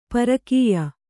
♪ parakīya